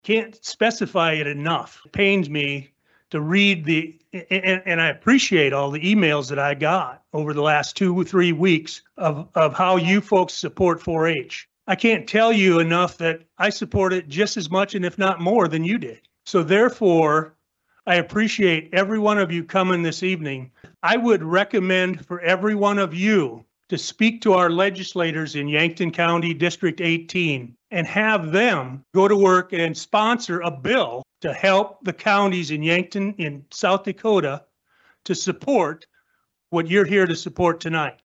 During an impassioned speech at Tuesday’s commission meeting, Commission Chair, John Marquardt, discussed some of the feedback the county has received over the last few weeks.